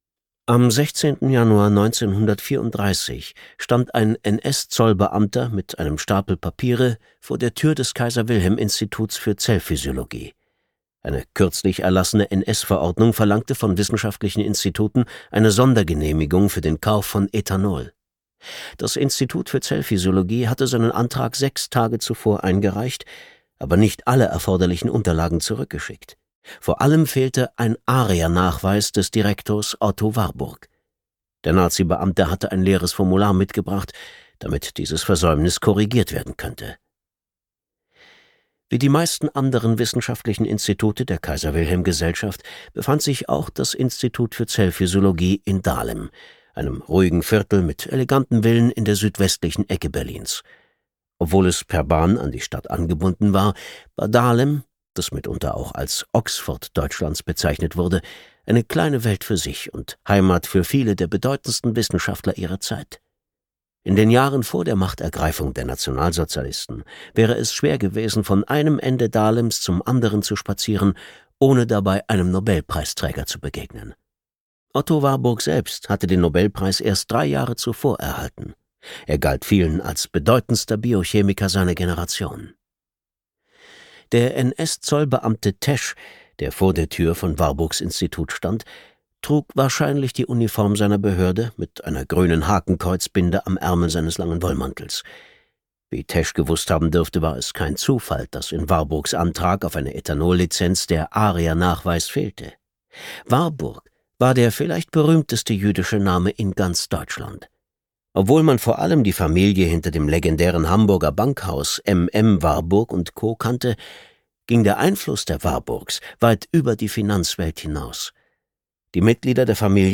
Gekürzt Autorisierte, d.h. von Autor:innen und / oder Verlagen freigegebene, bearbeitete Fassung.
Switch Studio, Berlin